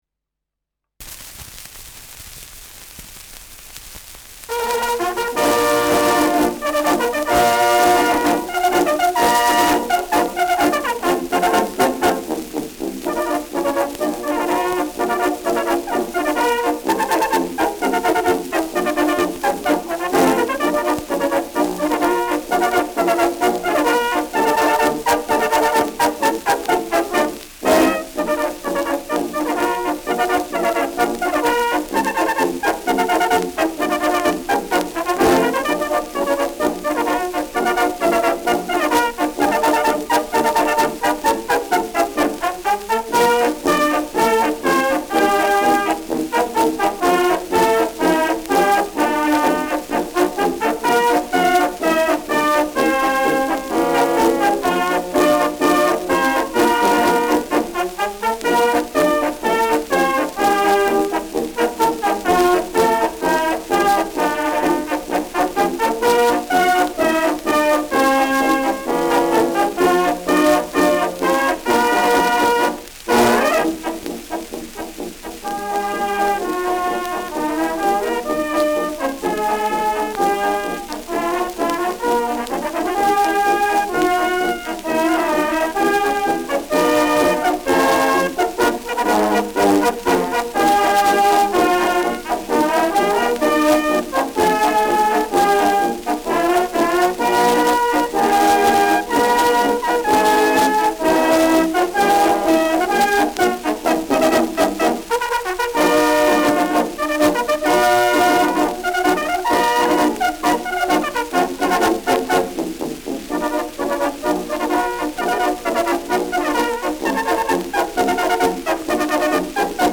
Schellackplatte
Tonrille: leichte Kratzer durchgängig
leichtes Rauschen
Militärmusik des k.b. 2. Ulanen-Regiments, Ansbach (Interpretation)
[Ansbach] (Aufnahmeort)